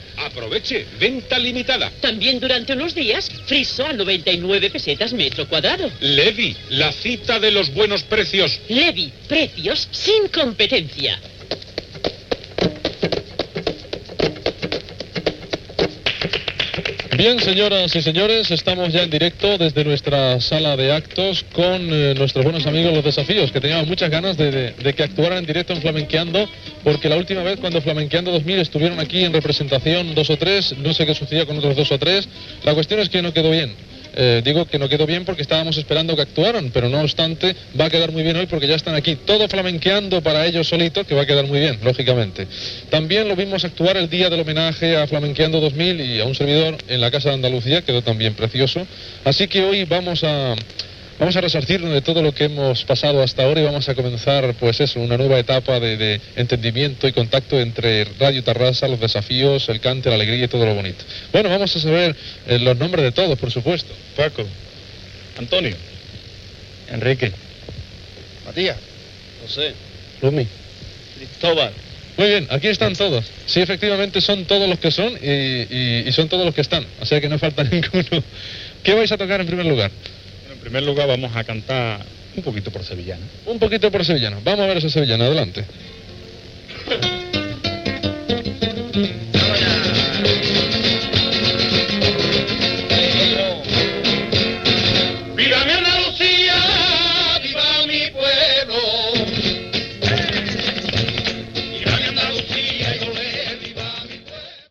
Publicitat, presentació del grup Los Desafíos a la sala d'actes de l'emissora
Musical